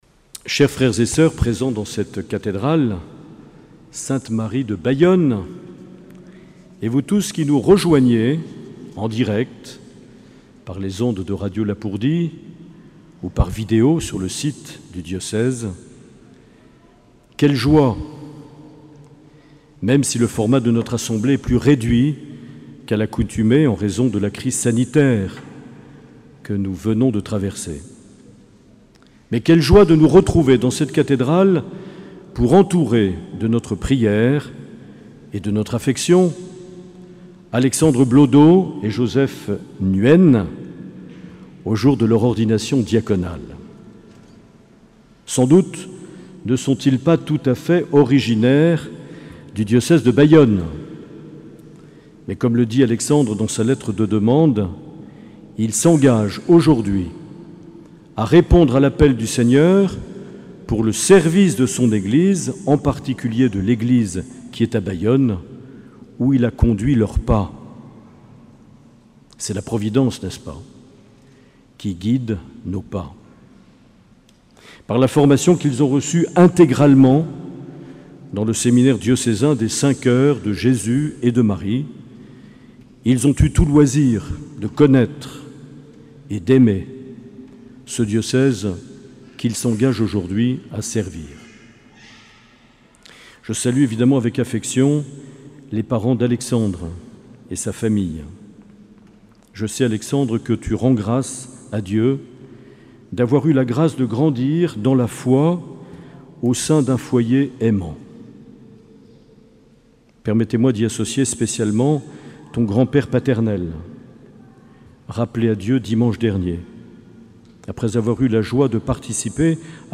Accueil \ Emissions \ Vie de l’Eglise \ Evêque \ Les Homélies \ 6 juin 2020
Une émission présentée par Monseigneur Marc Aillet